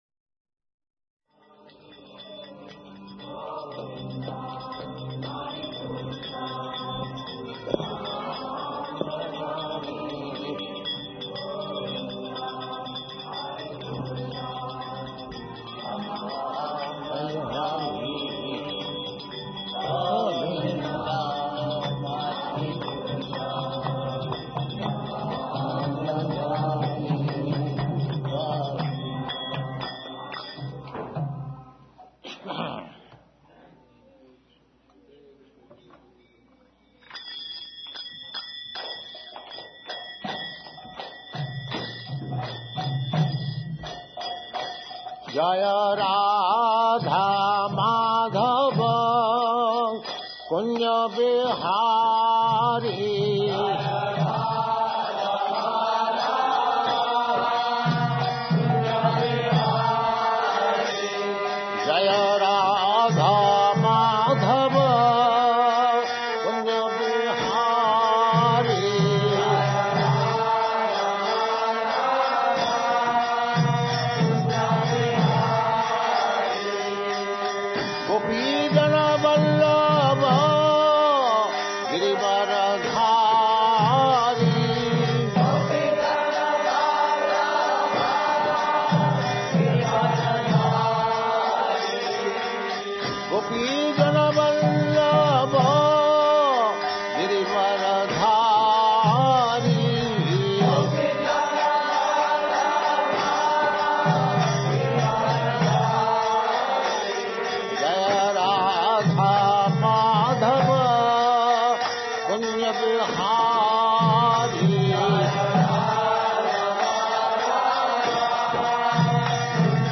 Srila Prabhupada lecture on Vamanadeva, The 15th Incarnation, Srimad Bhagavatam 1.3.19, September 24, 1972, Los Angeles